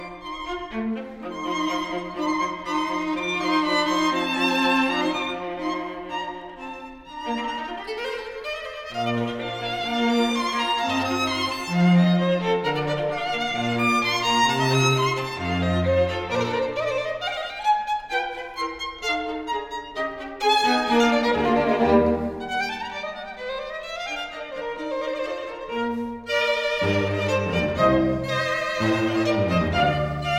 "enPreferredTerm" => "Musique de chambre"